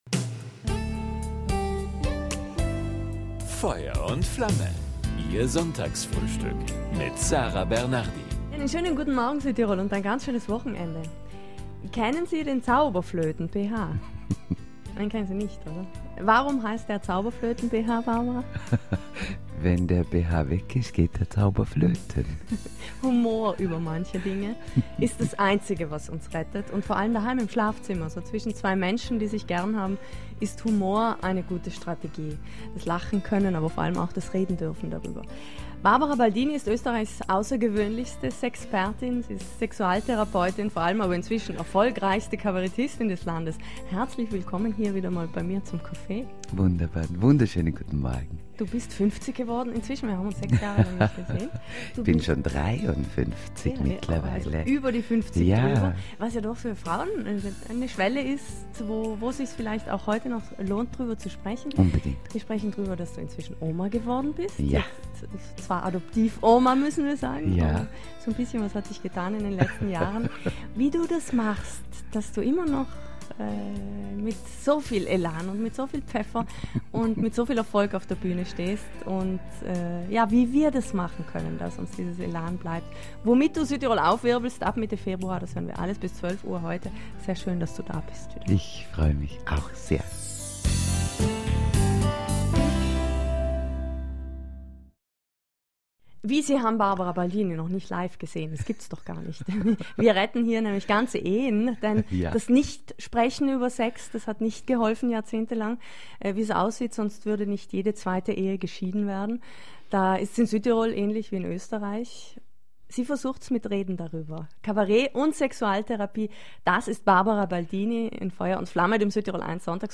Zum Nachhören: Barbara Balldini im Interview